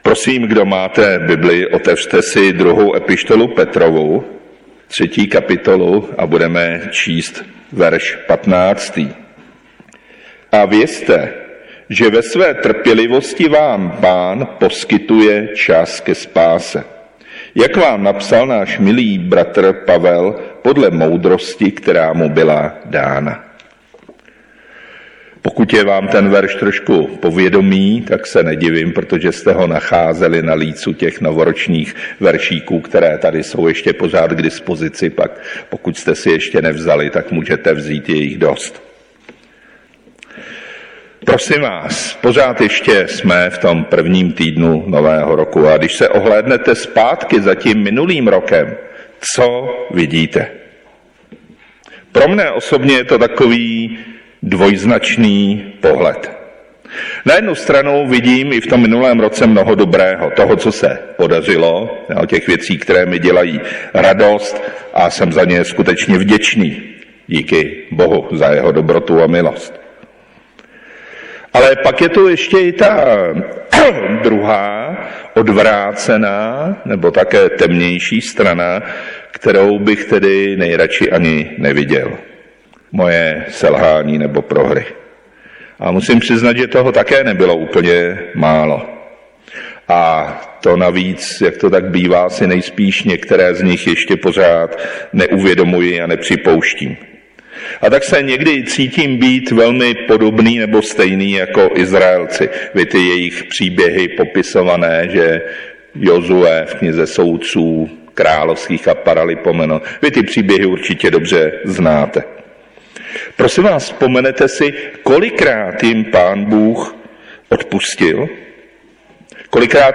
Nedělní bohoslužby Husinec přehrát